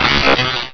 Cri de Serpang dans Pokémon Rubis et Saphir.